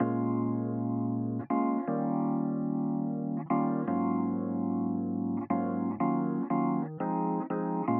17 Elpiano PT1.wav